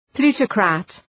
Προφορά
{‘plu:tə,kræt}